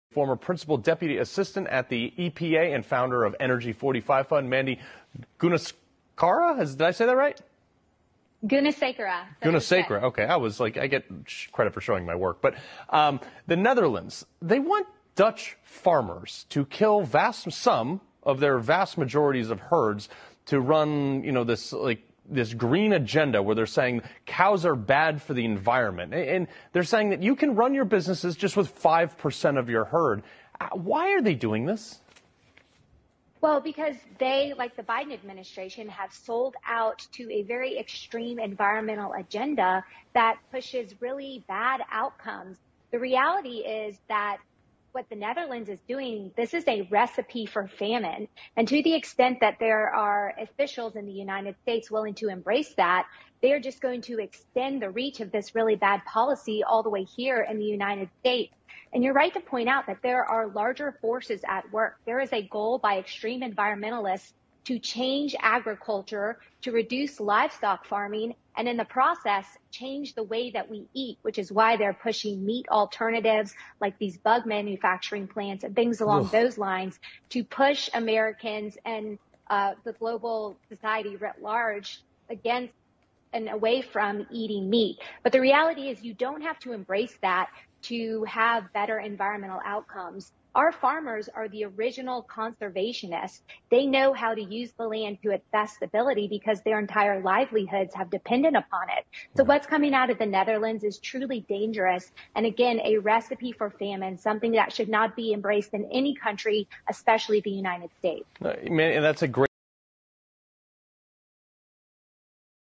Diese Frau bringt es auf den Punkt. Die vorgetäuschte Klimakrise wird dazu benutzt, die Nahrungsmittelversorgung auszulöschen, um eine Hungersnot zu verursachen...